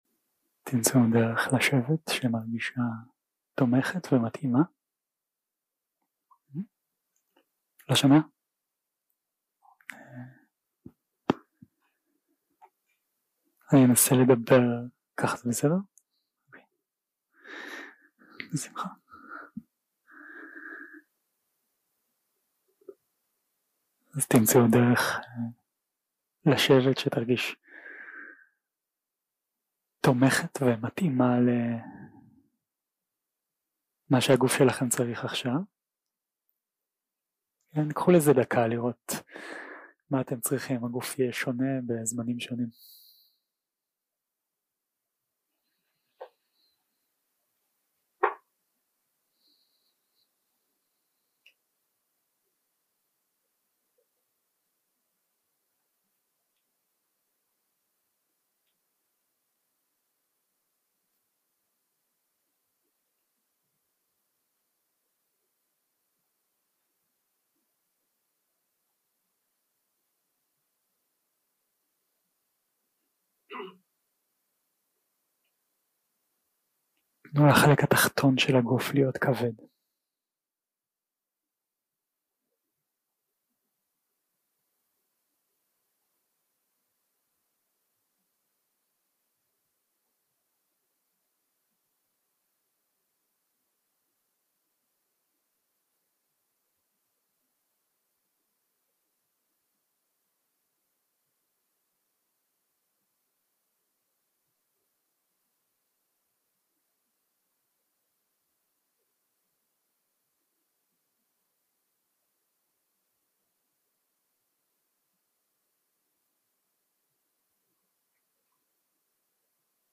יום 5 - הקלטה 11 - ערב - מדיטציה מונחית
סוג ההקלטה: מדיטציה מונחית